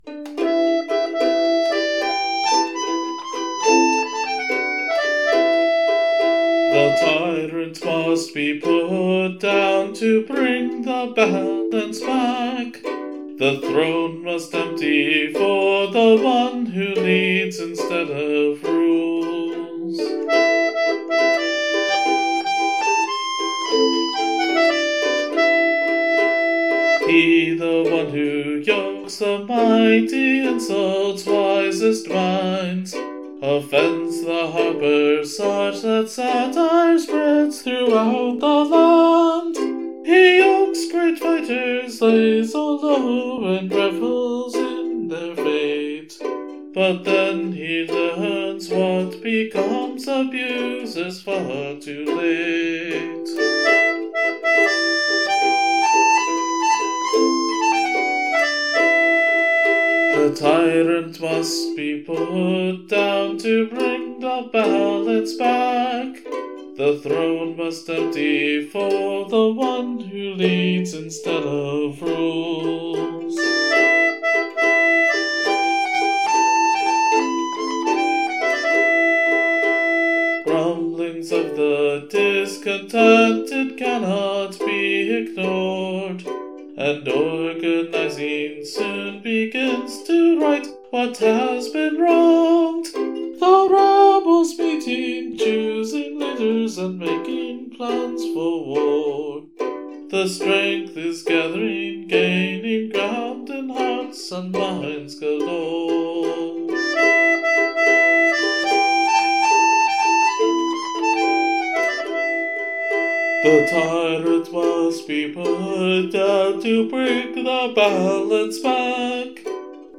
Musically, the most interesting aspect is probably the rhythm. The quick almost-grace-notes help emphasize and ornament certain syllables to keep things lively. Melodically, this is also a bit challenging due to jumping around quite a bit, leading me to wonder while recording the vocals what kind of fool would write it that way. Because one of the skills Lugh demonstrated was the harp, I decided to use that as the main accompanying instrument, which is always a good challenge for me as a relative novice on that instrument.